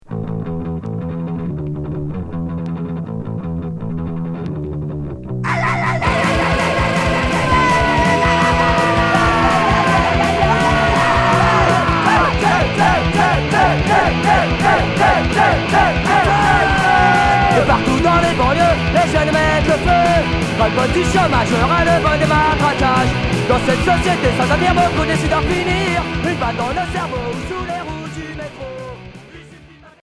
Anarcho punk